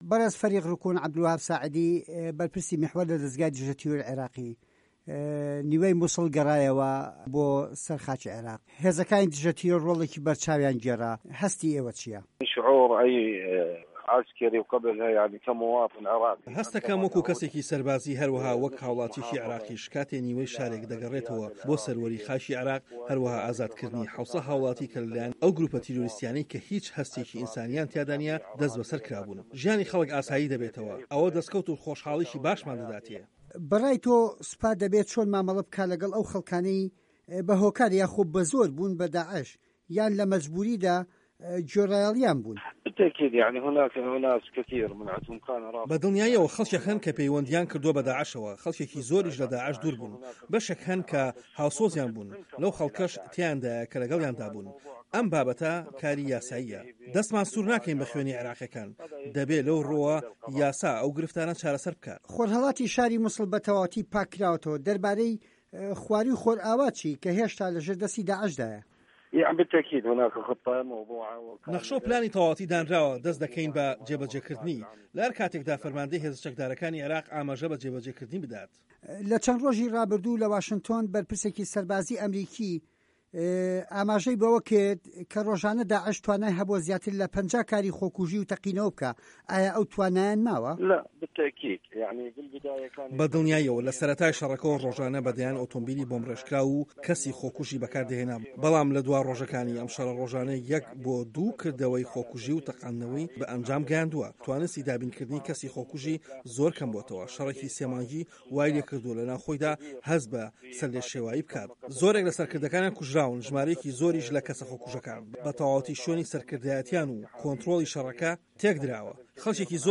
وتووێژ لەگەڵ فه‌ریق روکن عه‌بدولوه‌هاب ئه‌لساعدی